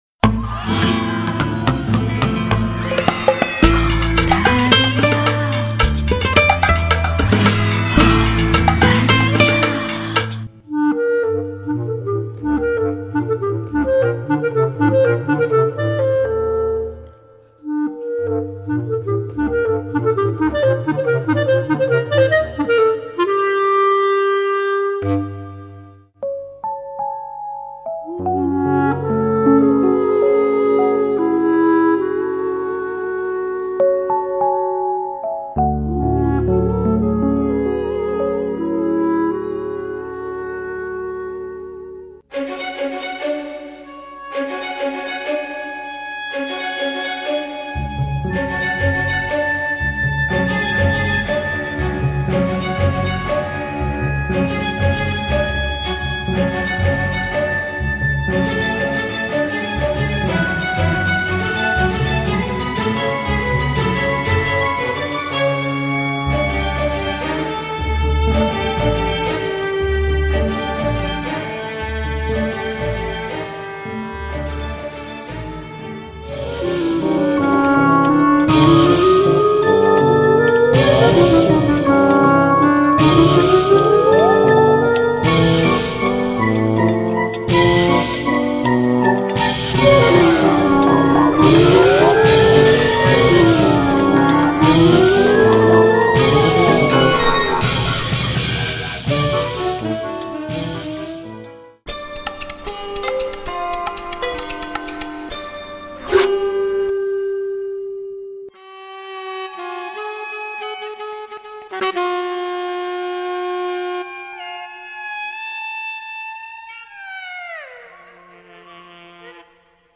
.Violon
Soprano
flûte
Guitare